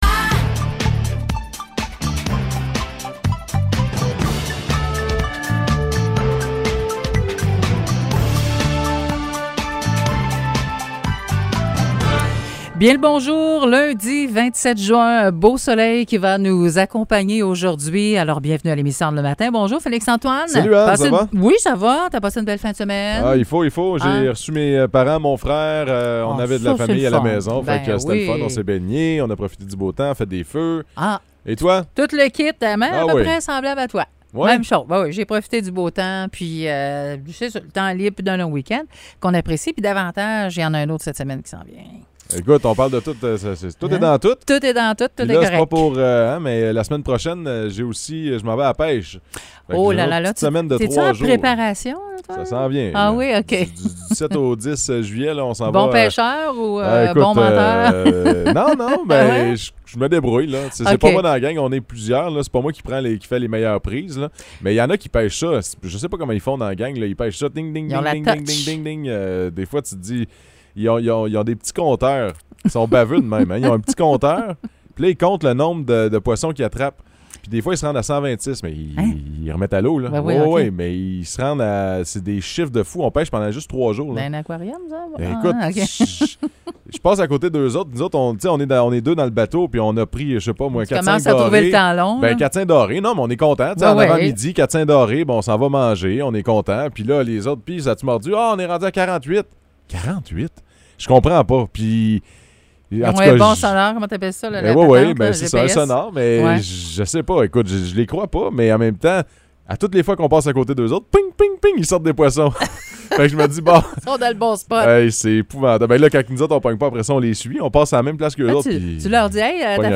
Nouvelles locales - 27 juin 2022 - 9 h